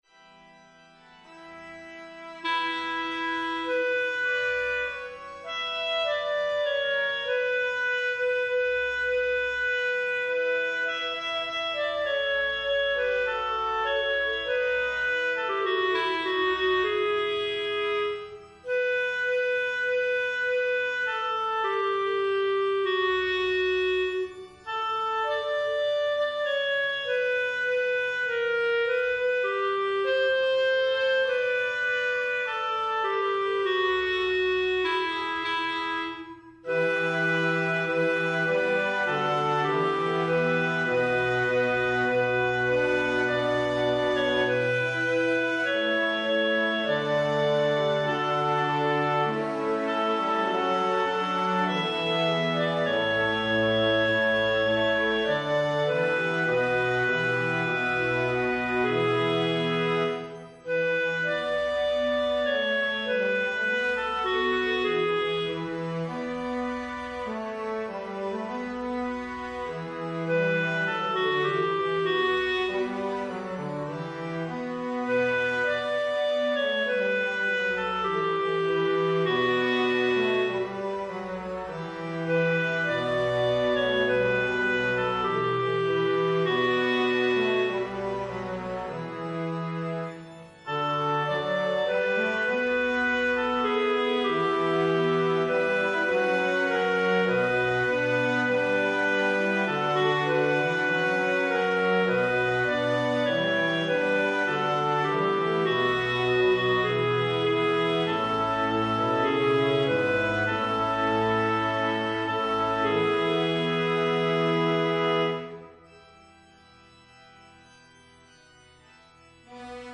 Soprano2
Evensong Setting